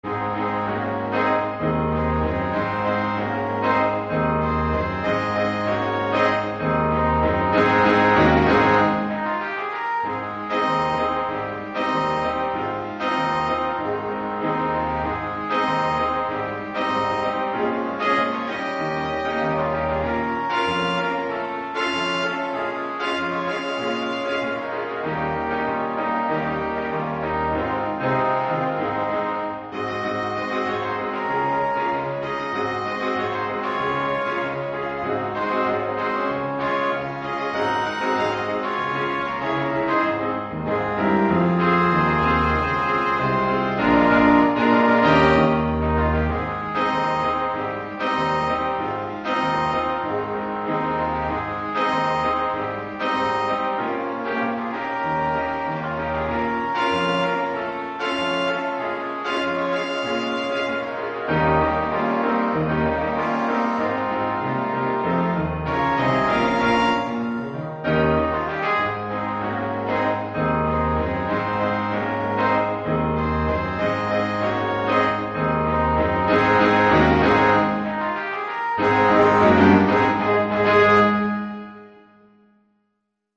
5-Part Ensemble
Ensemble gemischt
Keyboard, Drums & Percussions optional.